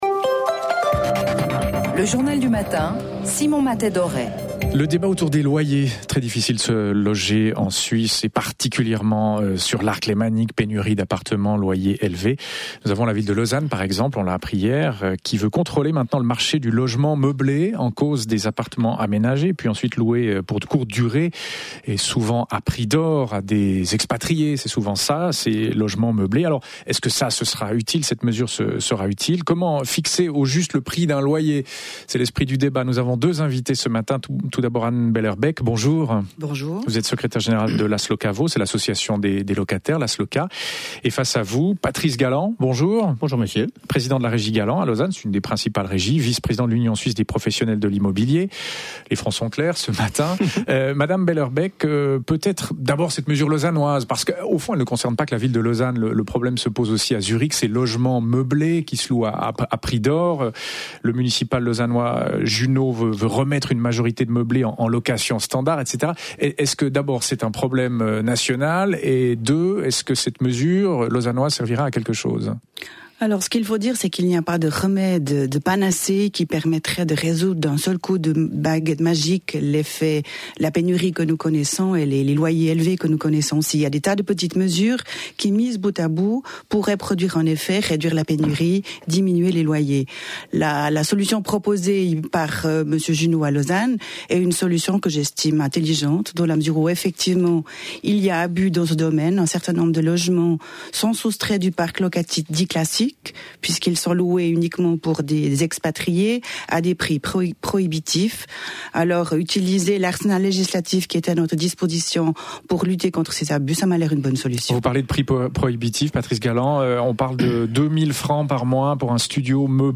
Le débat